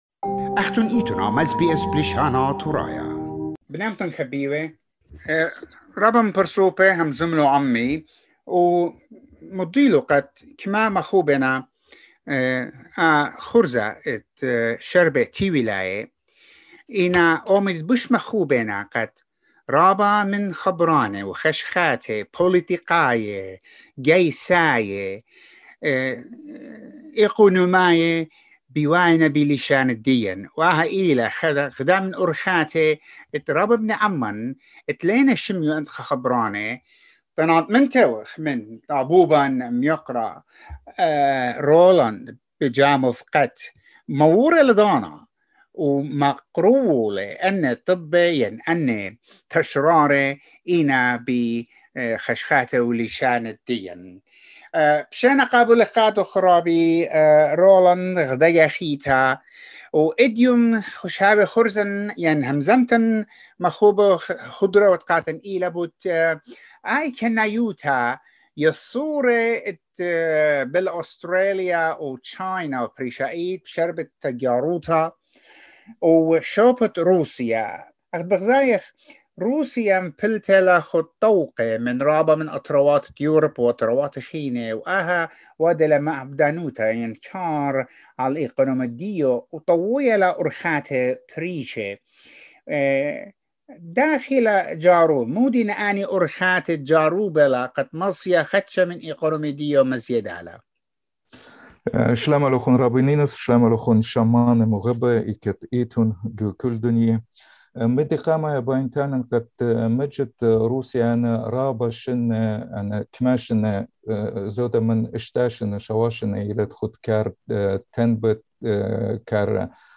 This is a weekly report